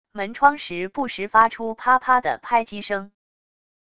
门窗时不时发出啪啪的拍击声wav音频